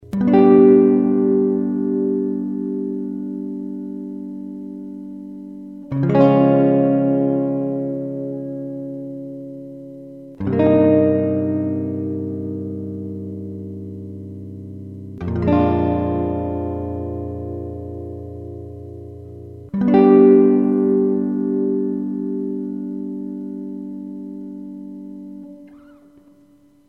Spring reverb effect unit with limiter system and a special stereo mode.
demo guitar demo 1